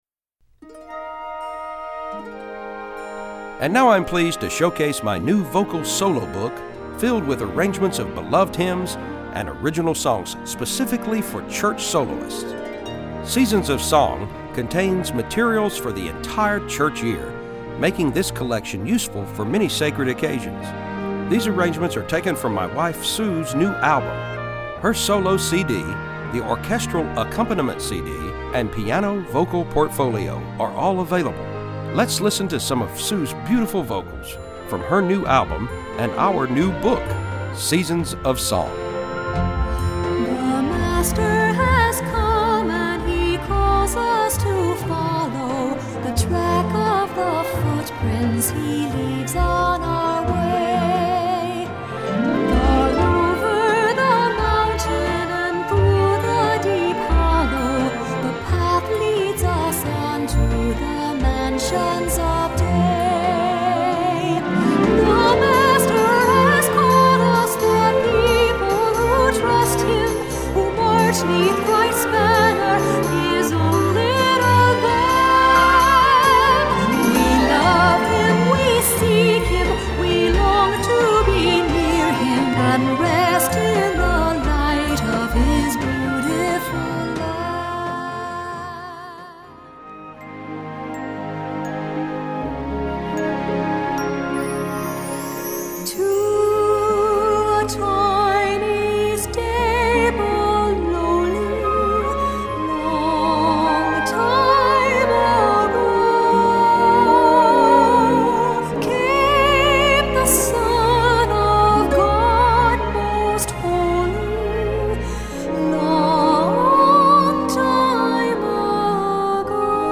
Voicing: Vocal Sacr